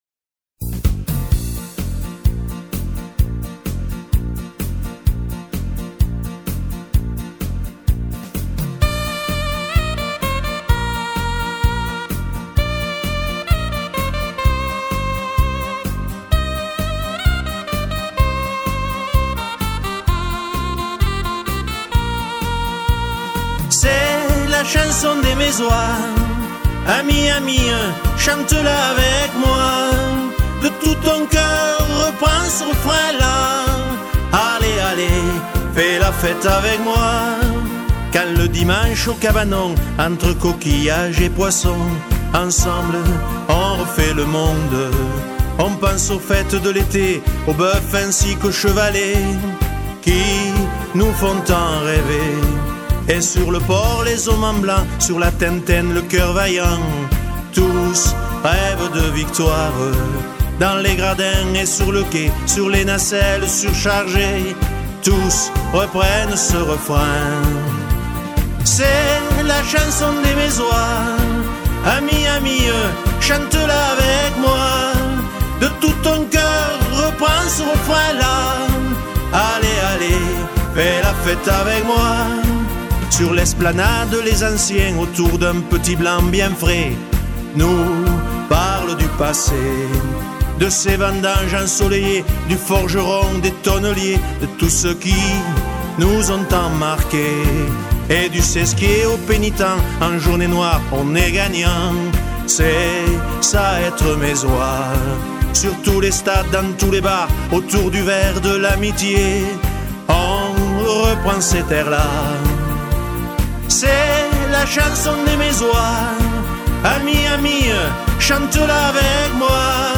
danse : marche
Genre strophique
Pièce musicale éditée